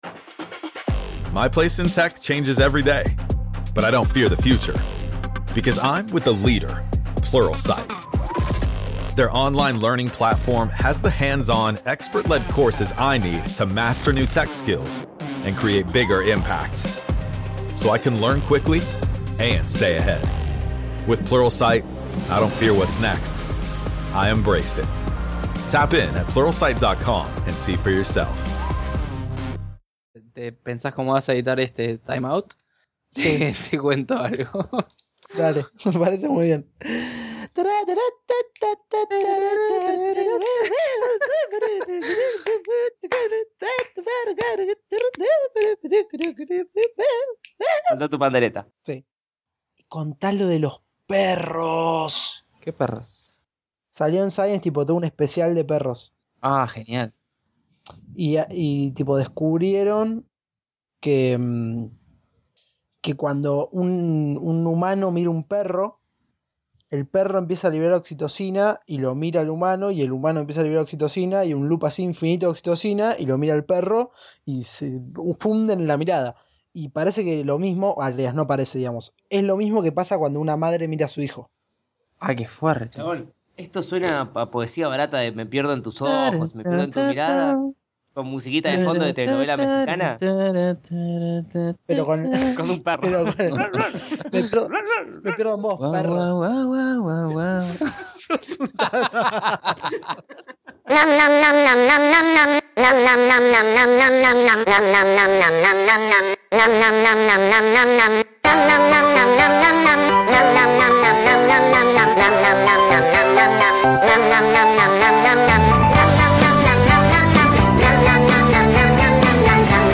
En una charla atravesada todo el tiempo por gatos y con una pequeña tormentota de fondo charlamos de dientes, idiomas felices y el buen momento de la divulgación científica argentina.